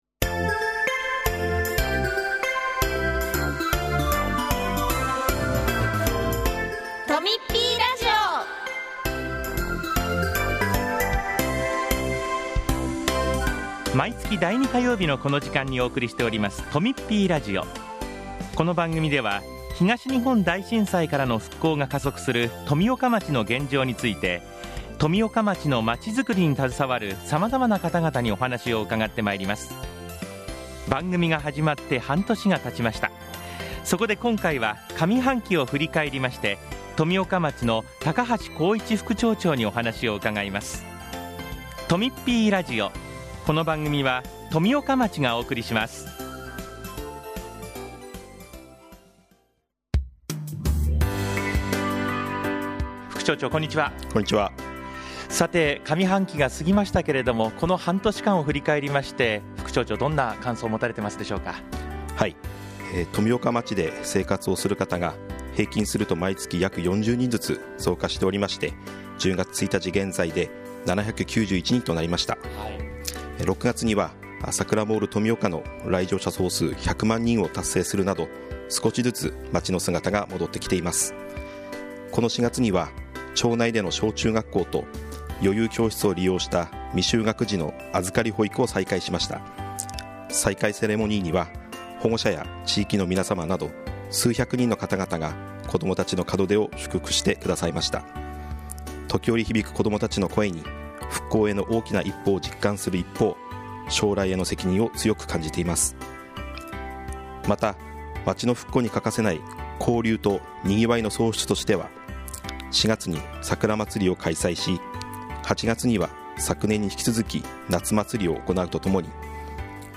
今回は、高橋副町長出演の「上半期を振り返り」のインタビューです。その他、町からのお知らせもあります。